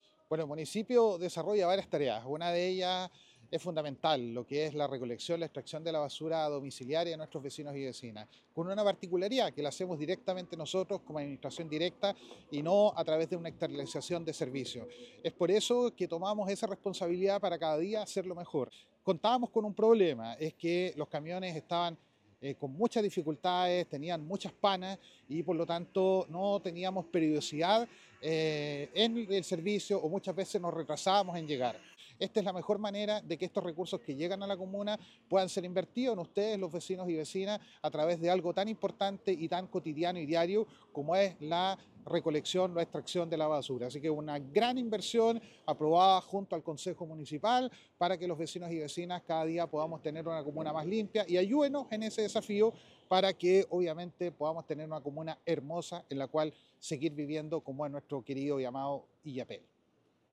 El alcalde de Illapel, Denis Cortés Aguilera, valoró esta importante adquisición: